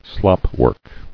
[slop·work]